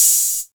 • Open High-Hat Sample B Key 21.wav
Royality free open high-hat sound tuned to the B note. Loudest frequency: 8361Hz
open-high-hat-sample-b-key-21-QvH.wav